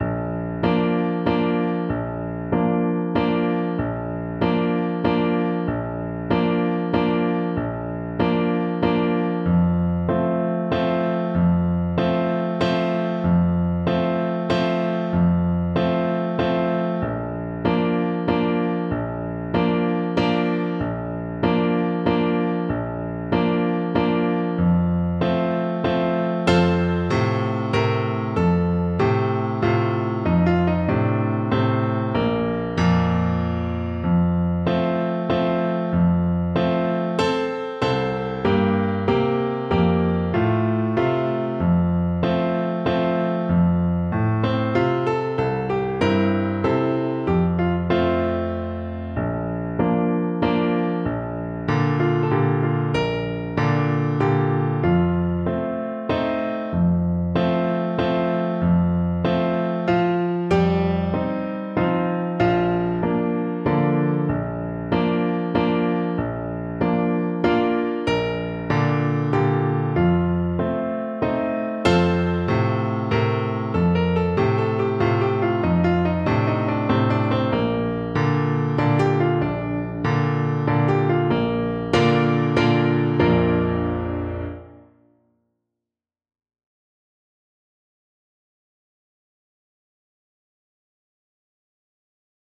Alto Saxophone
Traditional Music of unknown author.
Allegretto = c.140
3/4 (View more 3/4 Music)
Traditional (View more Traditional Saxophone Music)
Saxophone pieces in Bb major